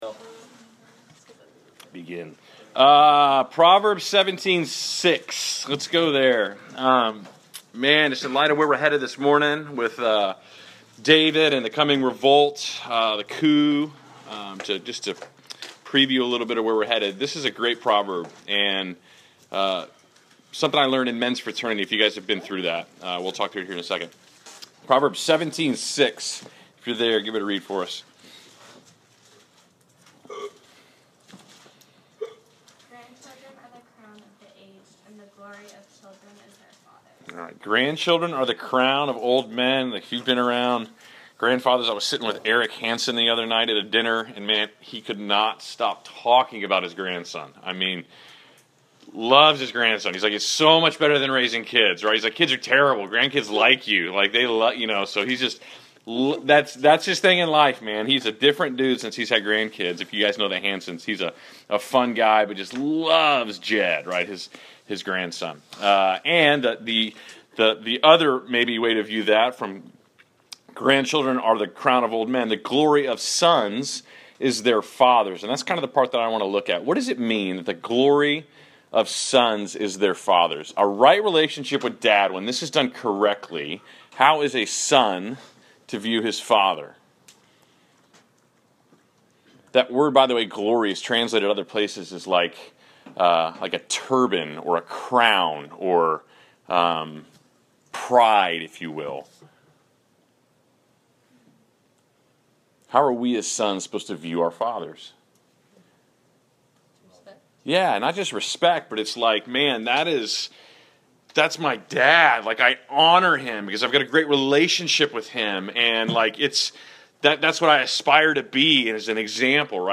Class Session Audio April 06